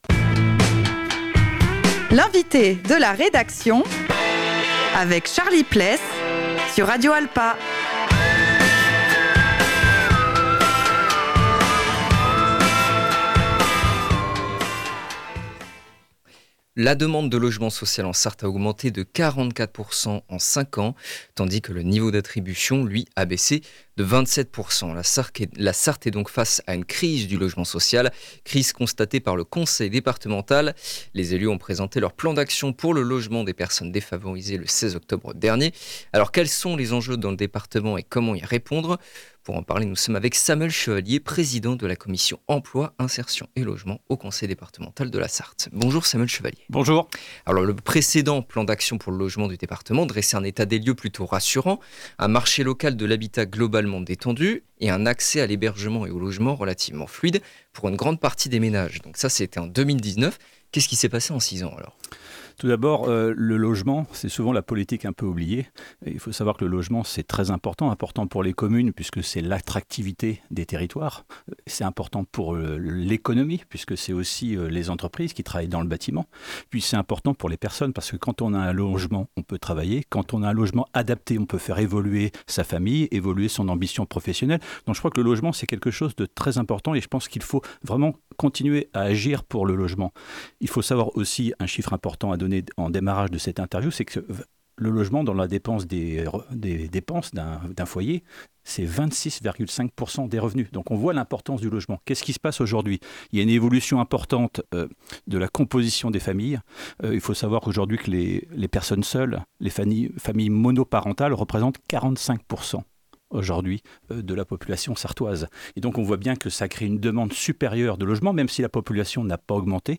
Alors quels sont les enjeux dans le département et comment y répondre ? Pour en parler nous sommes avec Samuel Chevallier, président de la commission emploi, insertion et logement au Conseil Départemental de la Sarthe.